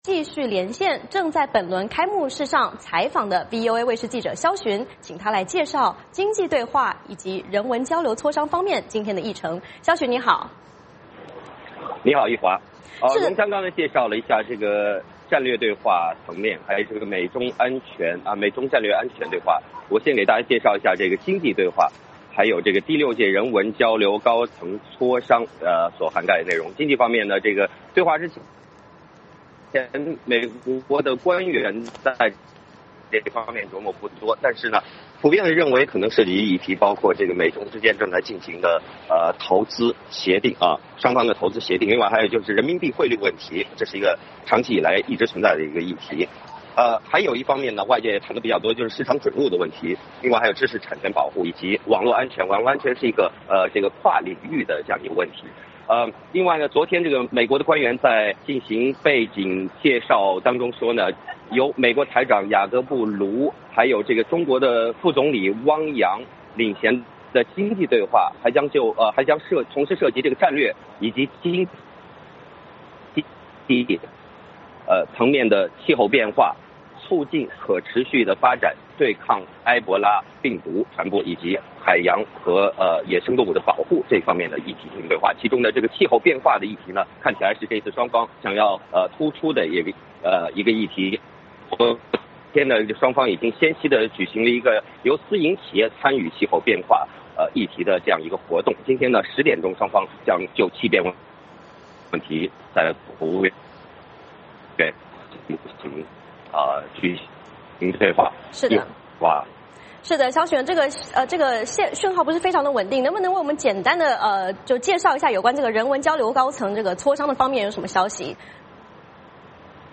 VOA连线：美中战略经济对话 经济会议相关议程及人文交流磋商